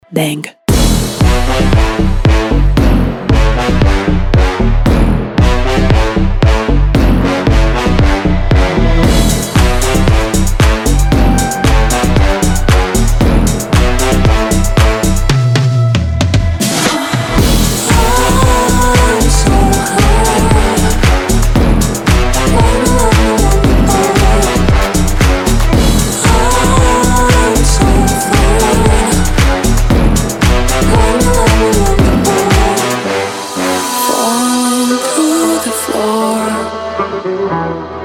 из Танцевальные